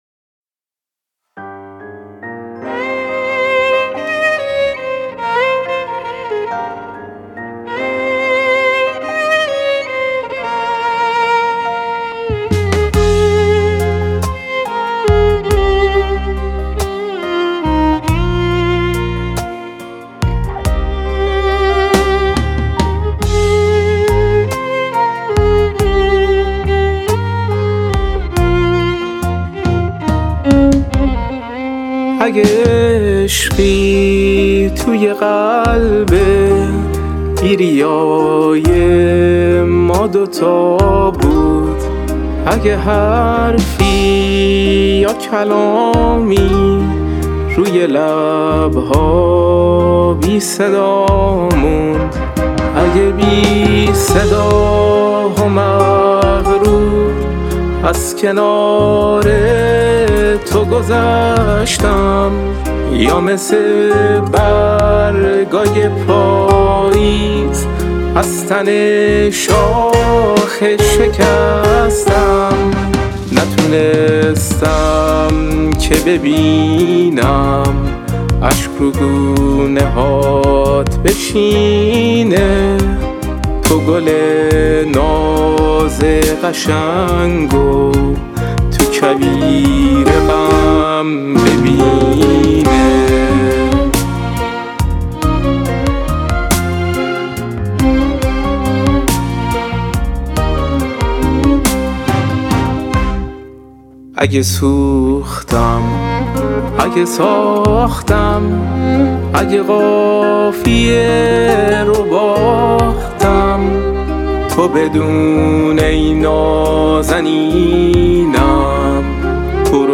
• تنظیم و گیتار باس
• ویولن